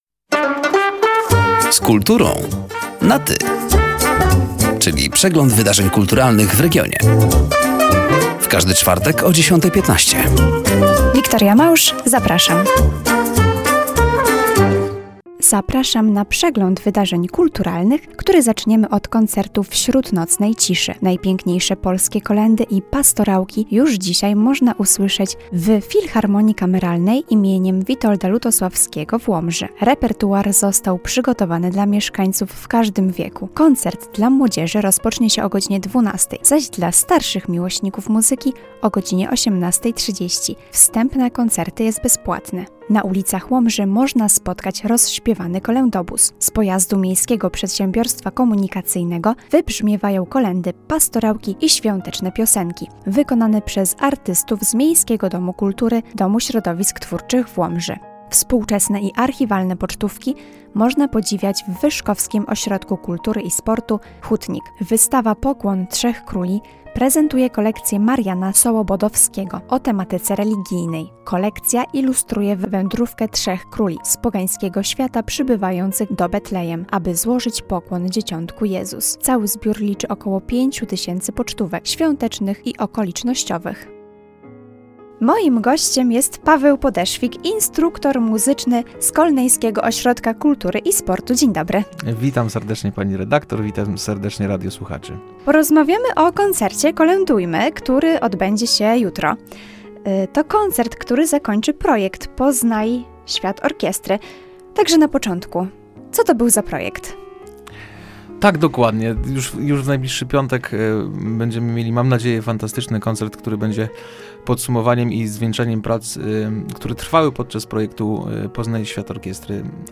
Zapraszamy do wysłuchania rozmowy oraz zapoznania się z wydarzeniami kulturalnymi: